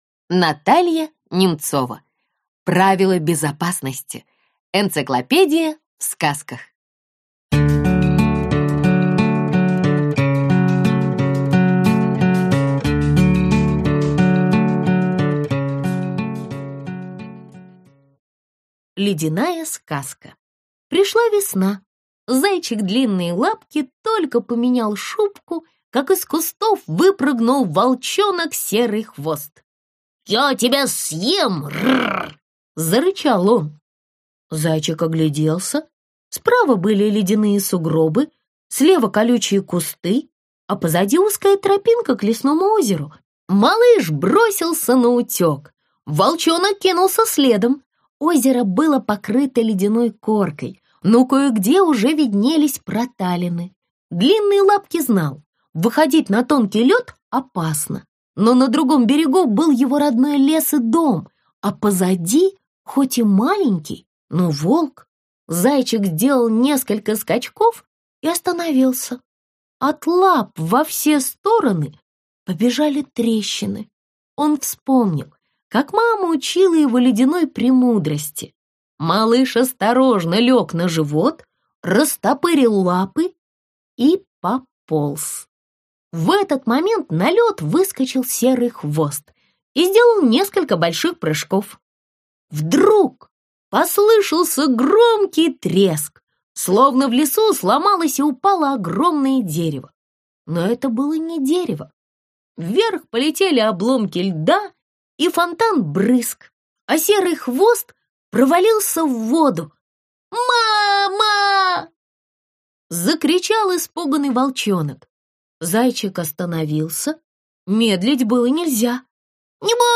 Аудиокнига Правила безопасности. Энциклопедия в сказках | Библиотека аудиокниг